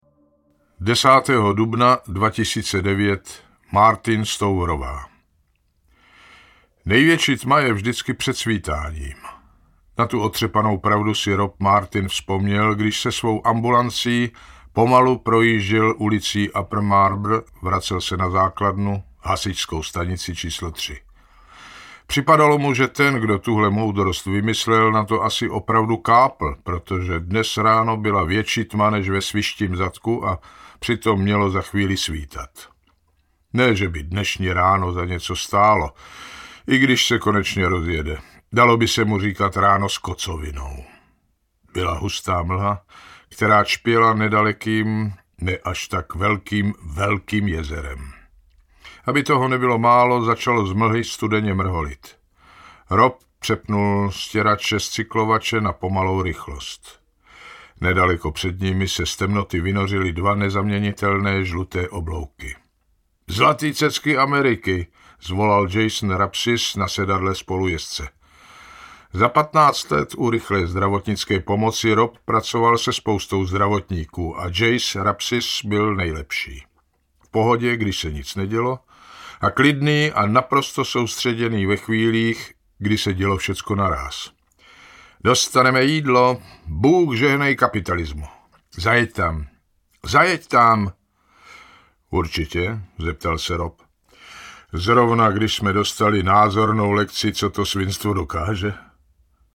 Vyberte Audiokniha 379 Kč Kniha vazba